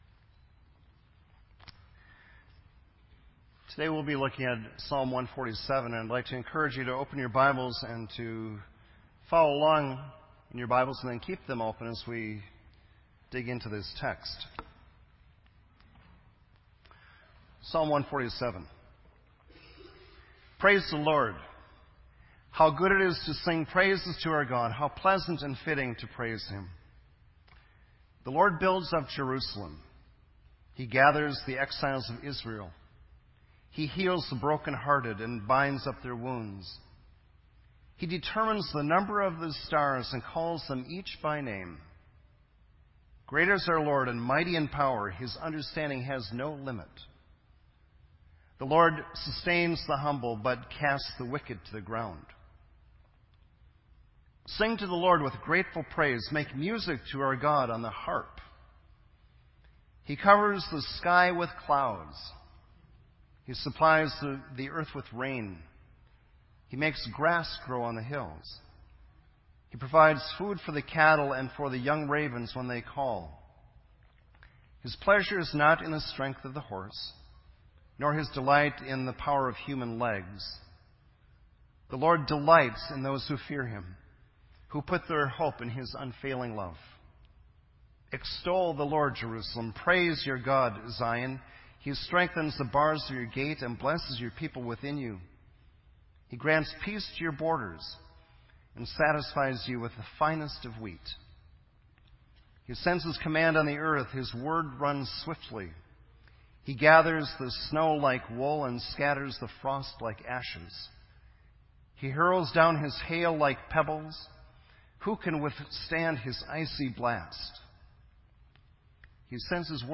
This entry was posted in Sermon Audio on August 17